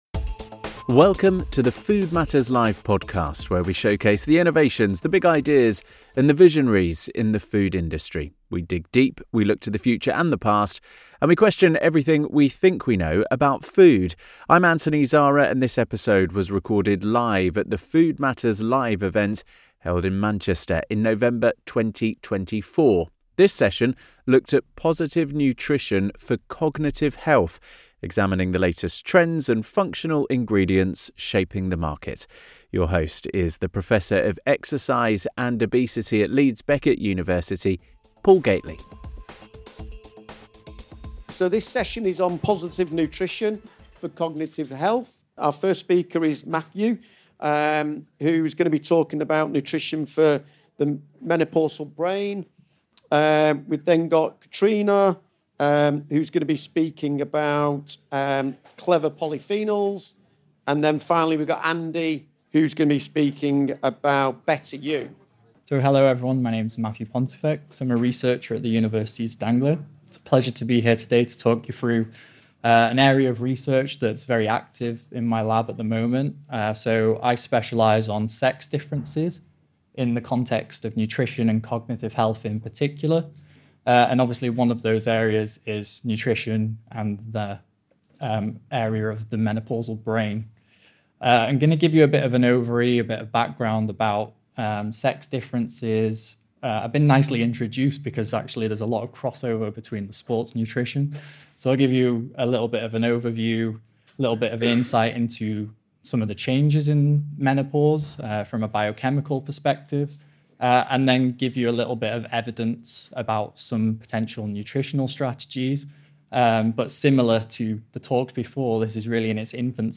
In this episode of the Food Matters Live podcast, recorded live at our event in Manchester in November 2024, our panel of experts delves into the world of positive nutrition and cognitive health.